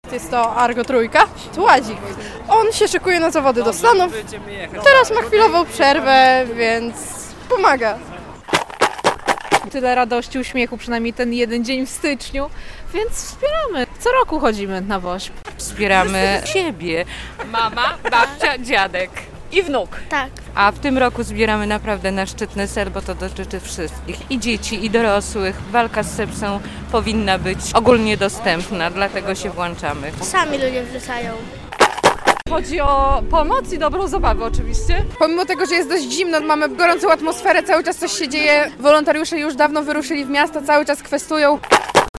Około 300 osób kwestuje w Białymstoku na rzecz Wielkiej Orkiestry Świątecznej Pomocy - relacja